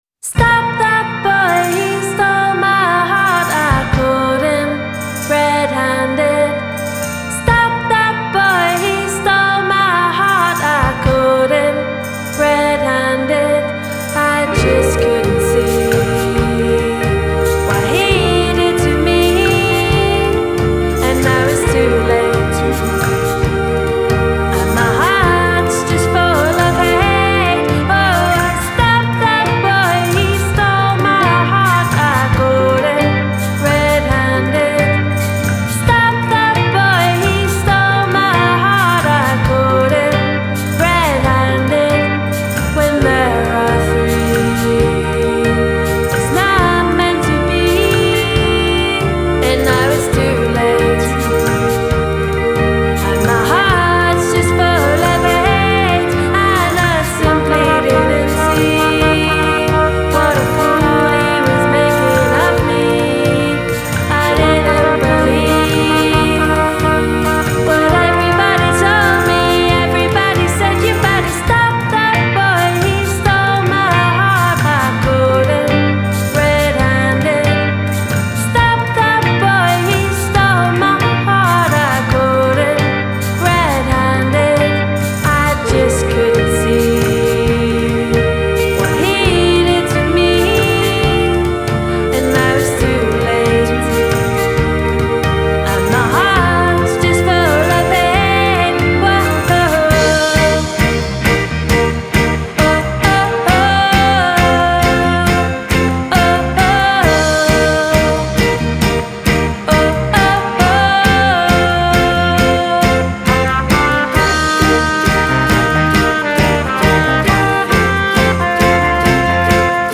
Genre: Indie Pop / Twee